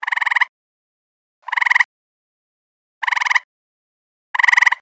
Single Frog Calls
Single Frog Calls is a free nature sound effect available for download in MP3 format.
Single Frog Calls.mp3